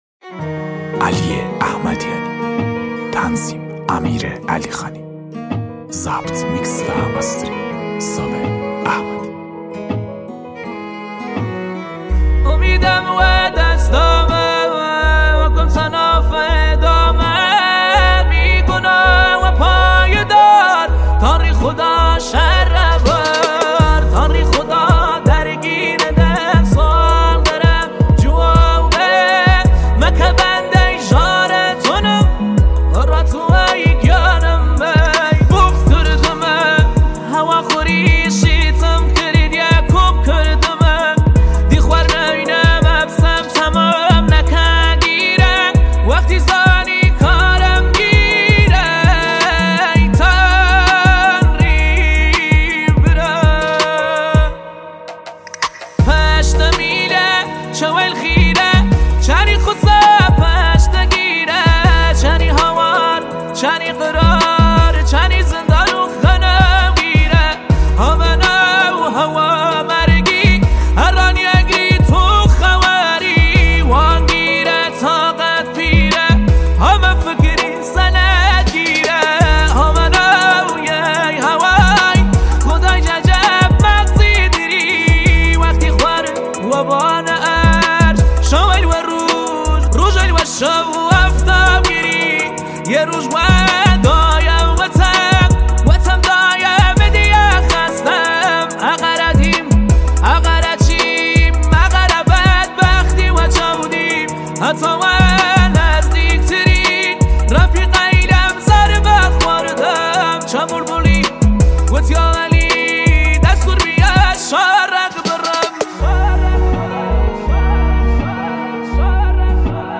دانلود آهنگ کردی